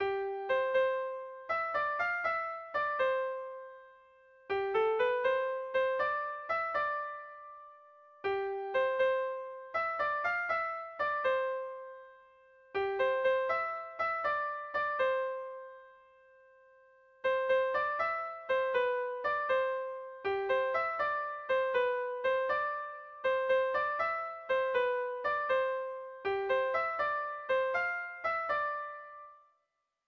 Air de bertsos - Voir fiche   Pour savoir plus sur cette section
Kontakizunezkoa
AAB1B2